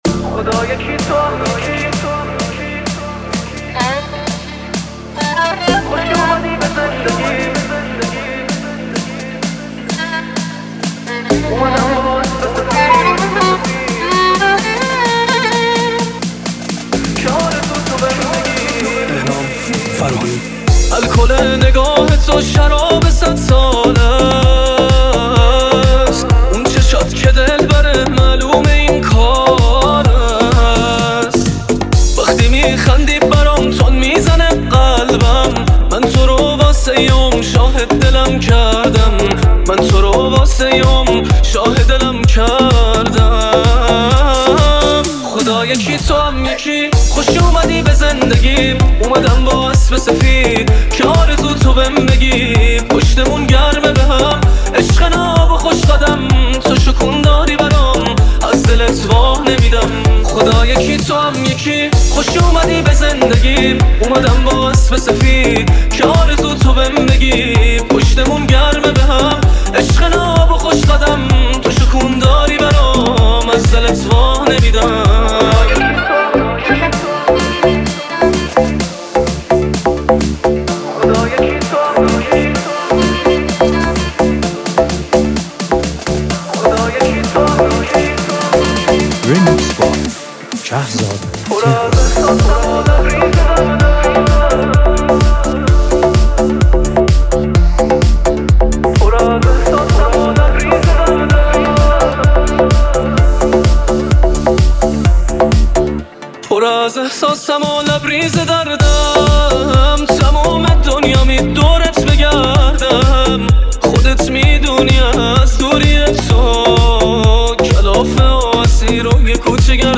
میکس پرانرژی